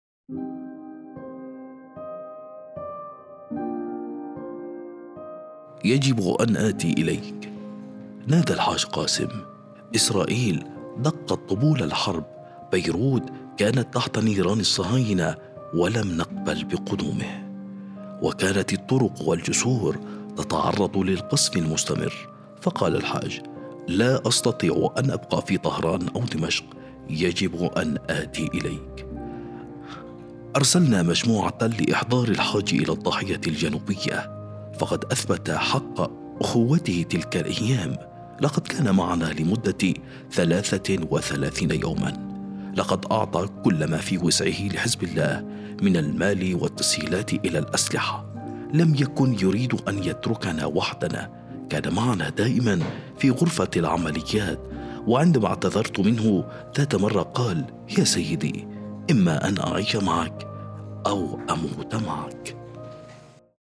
المصدر: مقتطف من كلمة السيد حسن نصر الله الأمين العام لحزب الله في لبنان
في مراسم اليوم السابع لاستشهاد الشهيد الحاج قاسم سليماني وشهداء المقاومة